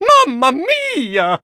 16 KB {{aboutfile |1=The sound Mario utters when Mia is his Mama.
Mario_(Mama_Mia)_-_Super_Mario_Party_Jamboree.oga